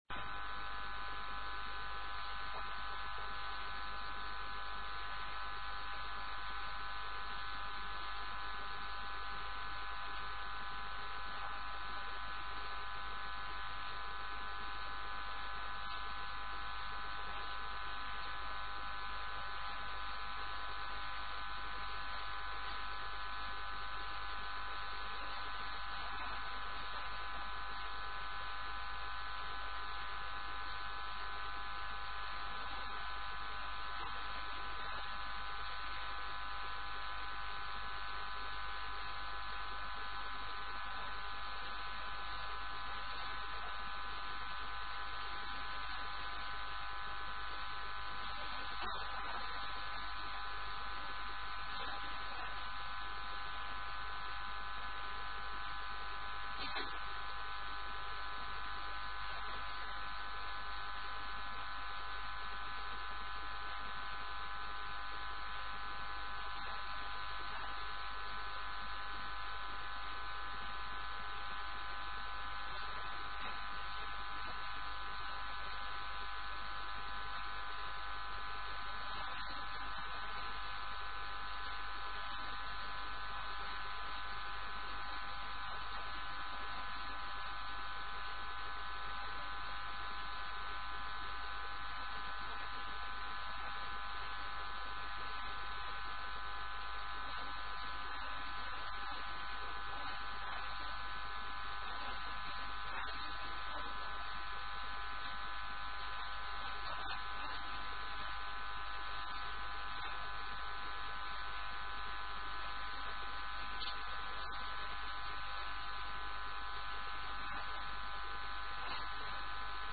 - الدروس العلمية - شرح كتاب مفتاح الجنة والوظيفة الشاذلية - الدرس الخامس عشر: من الصفحة 147 إلى الصفحة 157